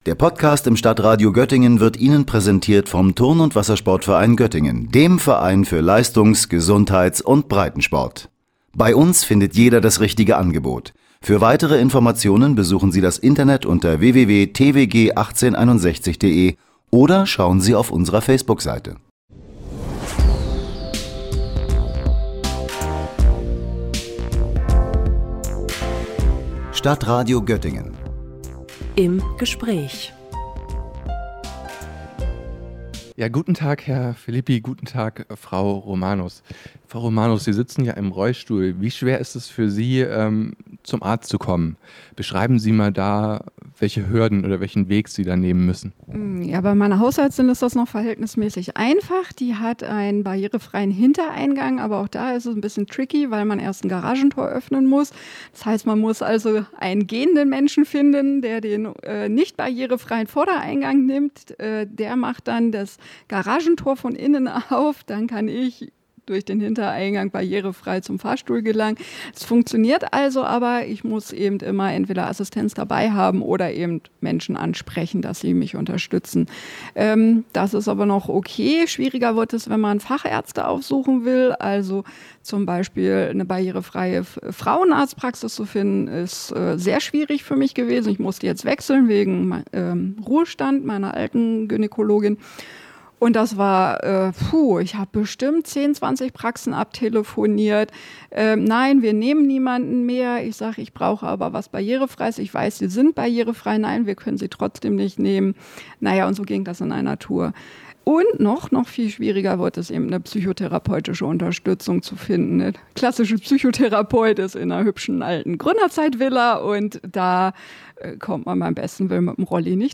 Wie groß sind die Probleme bei der Inklusion? Doppelinterview